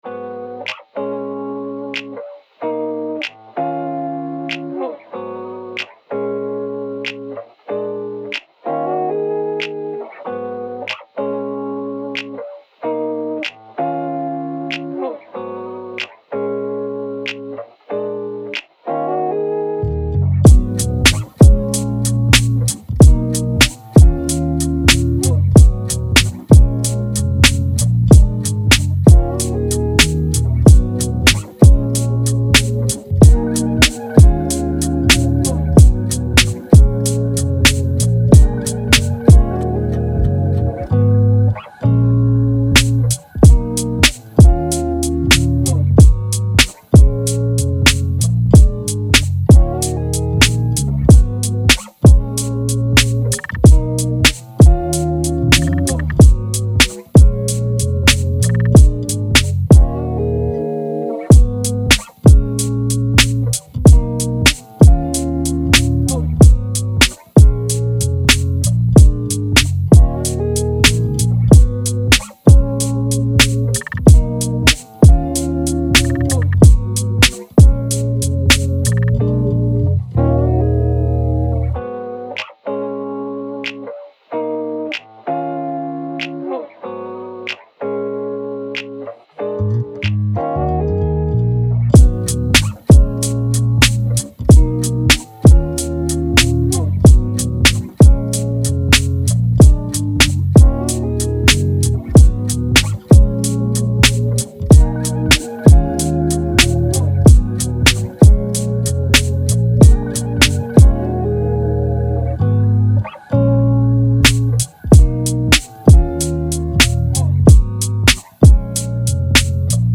Pop, R&B
Eb Min